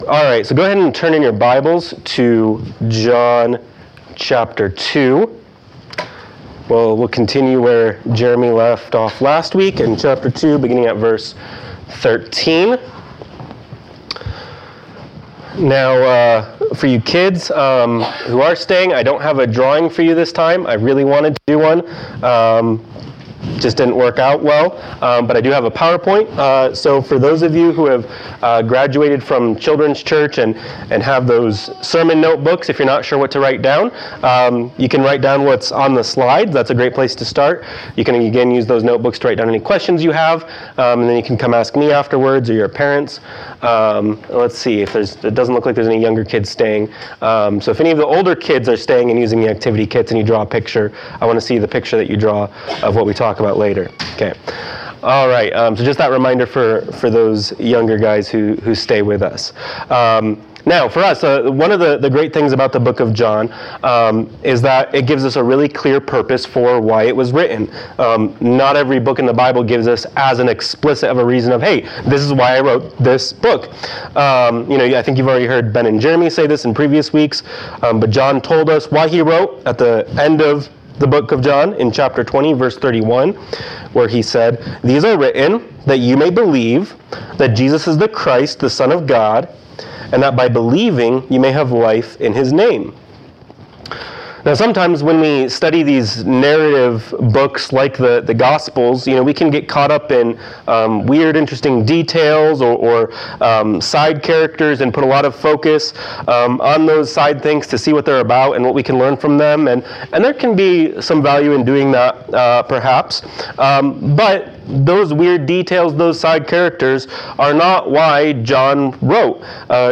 John 2:13-25 Service Type: Sunday Morning Worship « John 2:1-12 John 3:1-15 »